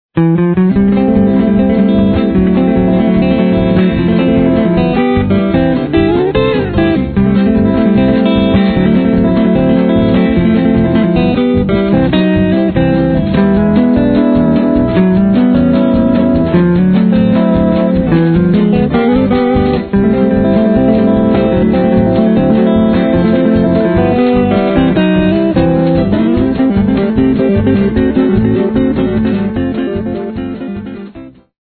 guitare (nylon & électrique)